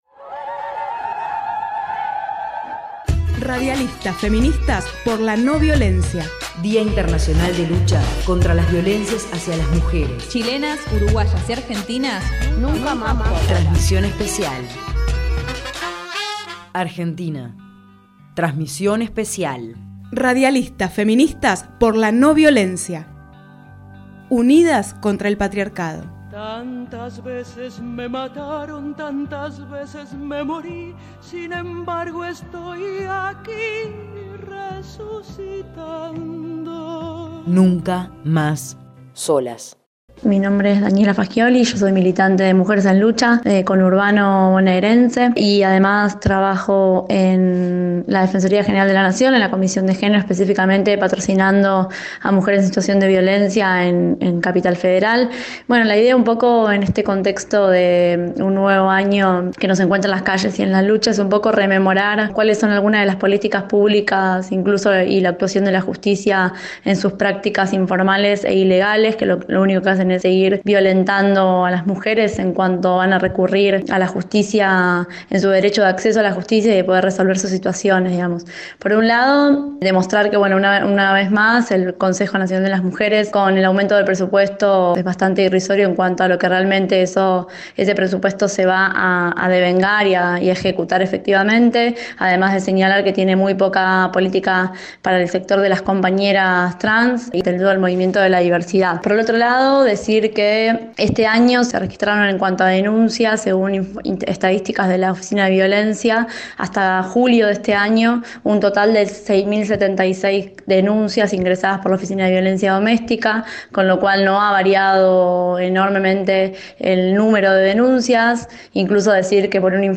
Con motivo del Día Internacional contra la Violencia hacia las Mujeres las Radialistas feministas realizamos un programa especial con voces de argentinas, chilenas y uruguayas.